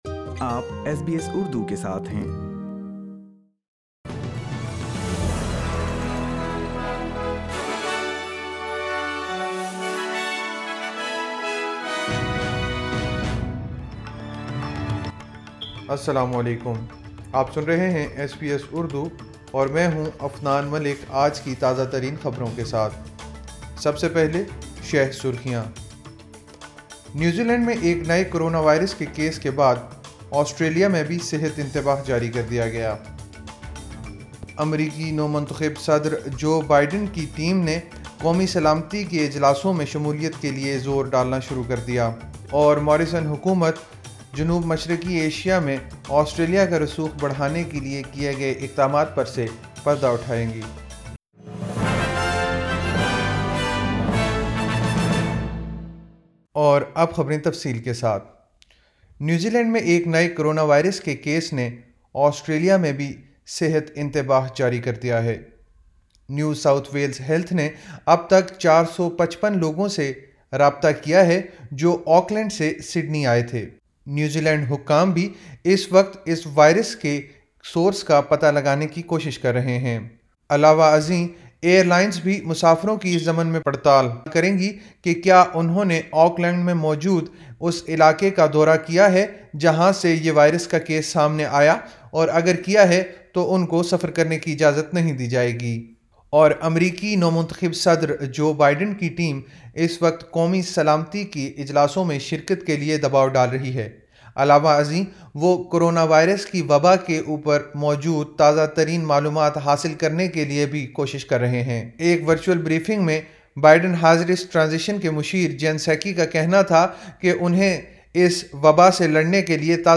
ایس بی ایس اردو خبریں 14 نومبر 2020